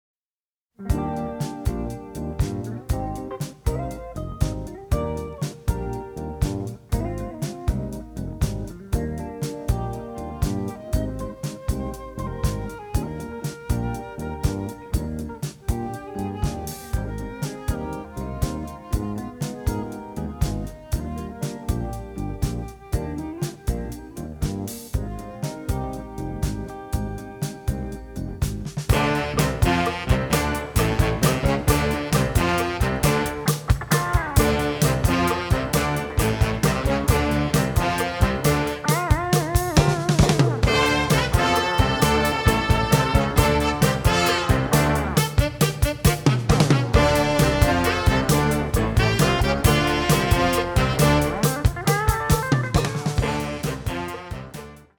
The music is descriptive, romantic and funny
All tracks stereo, except * mono